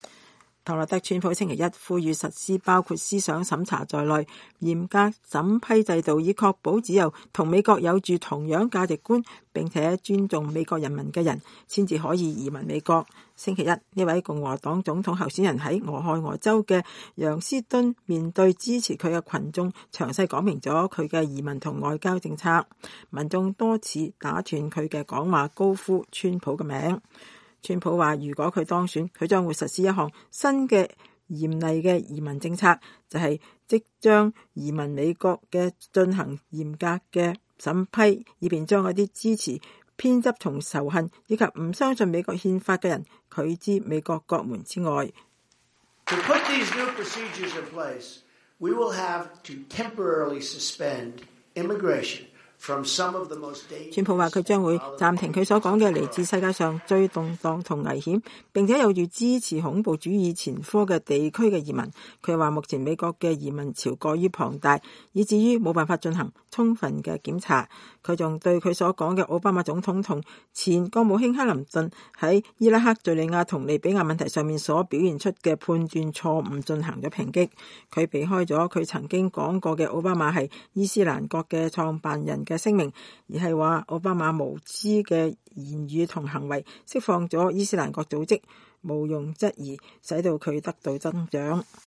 星期一，這位共和黨總統候選人在俄亥俄州的揚斯敦面對支持他的群眾詳細說明了他的移民和外交政策。人群多次打斷他的講話，高呼“川普、川普、川普!”